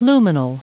Prononciation du mot lumenal en anglais (fichier audio)